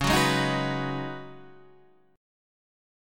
C#9sus4 chord